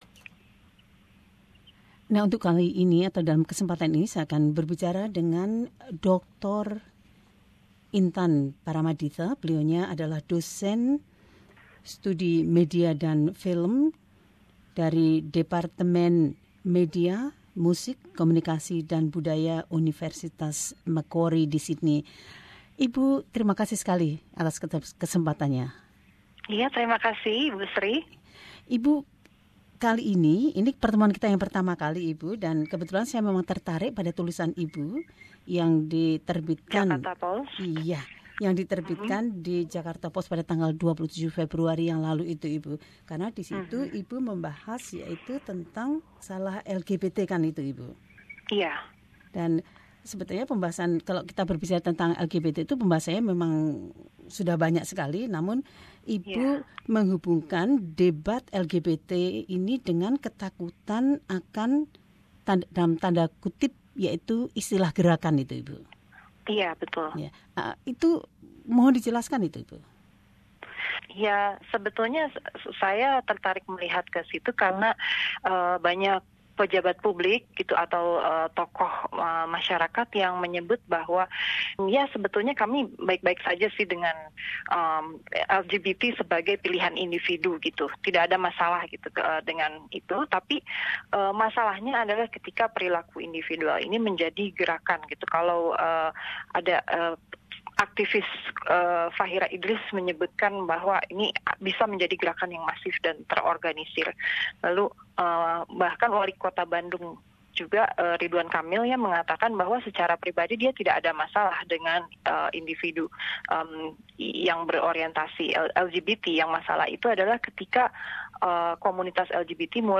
Gerakan LGBT masih menjadi momok bagi sebagian masyarakat Indonesia. DR Intan Paramaditha menjelaskan ulasannya tentang isu ini dalam wawancara berikut ini.